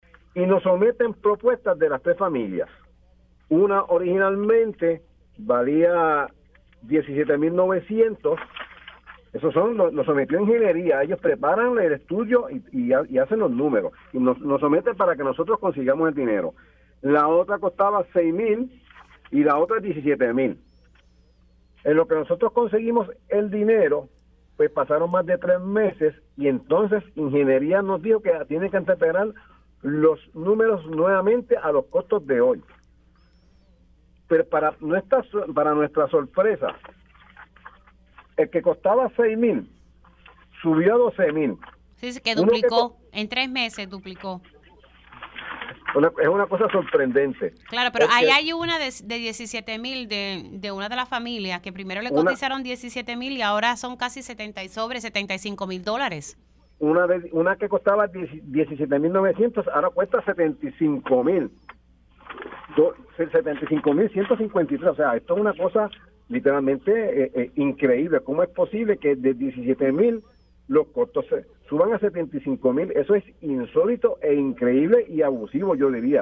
El alcalde de Maricao, Wilfredo ‘Juny’ Ruiz denunció en Pega’os en la Mañana que LUMA Energy duplicó el costo de unas propuestas que les sometieron para proveer servicio eléctrico a tres familias maricaeñas que no cuentan con luz desde hace 10 años.